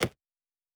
pgs/Assets/Audio/Sci-Fi Sounds/Interface/Click 4.wav at master
Click 4.wav